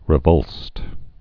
(rĭ-vŭlst)